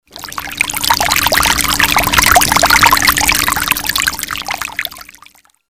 Water drain
Relax listening to water draining.
water-draining.mp3